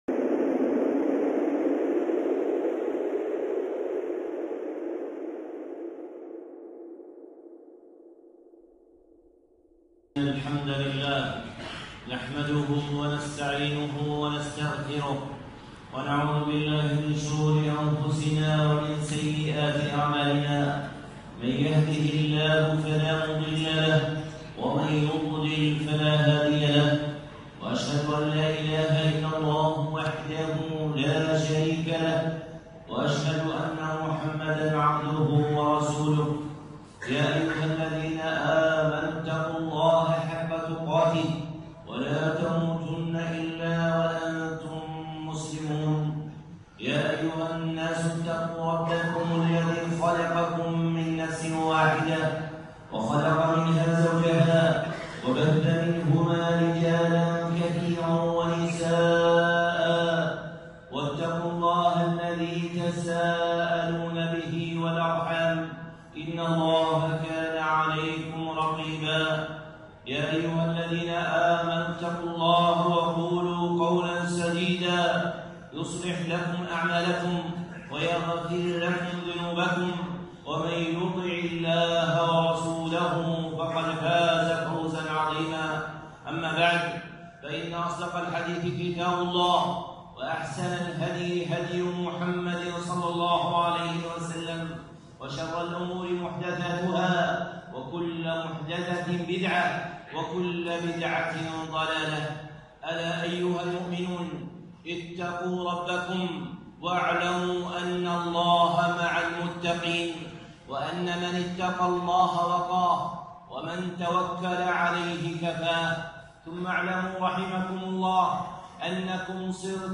خطبة (فضل ليلة القدر)